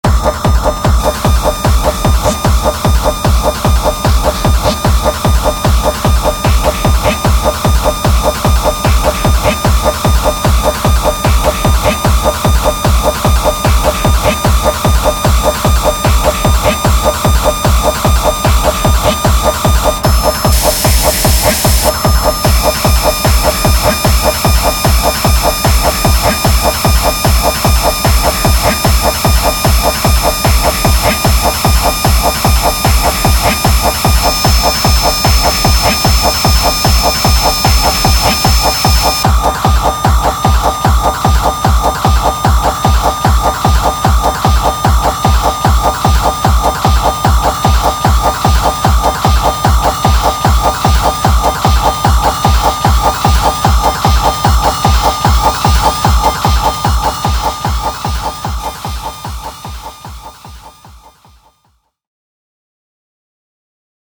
electro-industrial